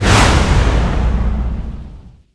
kwaesok_effect.wav